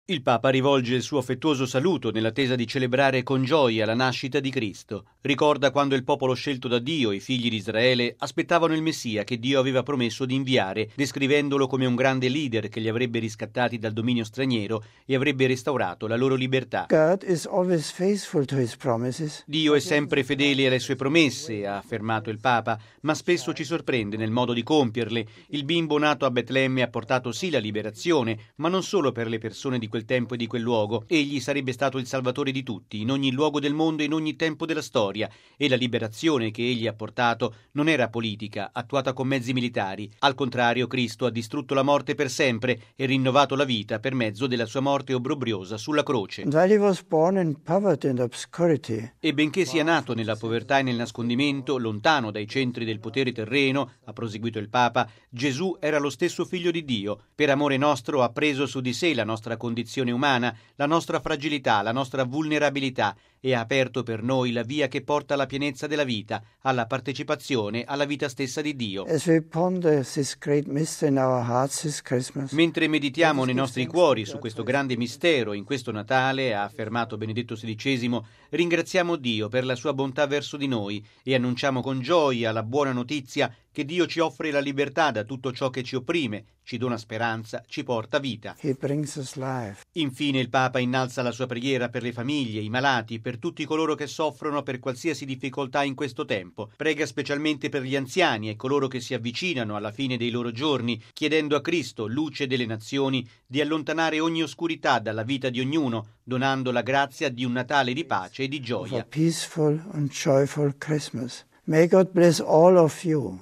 Una breve riflessione, registrata da Benedetto XVI mercoledì scorso in Vaticano, per la storica trasmissione dell’emittente britannica “Un pensiero al giorno”, in una ideale prosecuzione del viaggio apostolico compiuto in settembre.